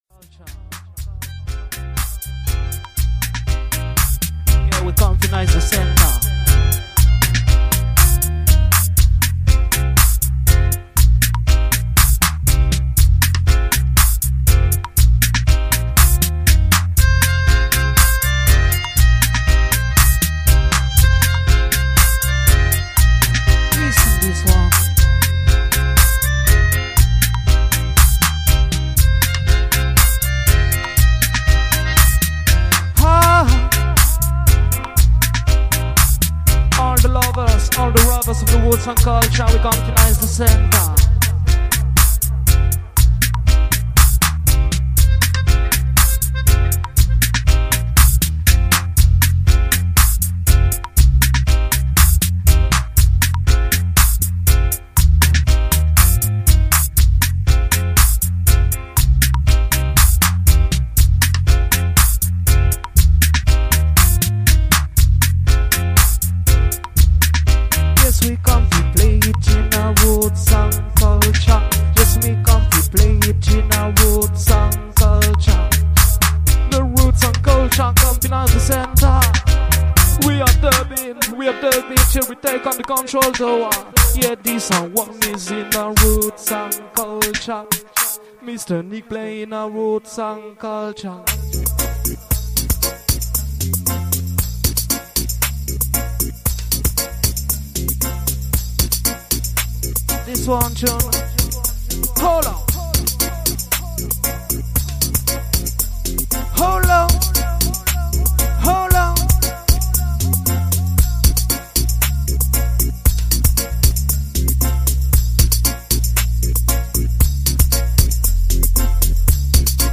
Live & Direct
Reggae-Dub-Steppa selection, vinyls & dubplates.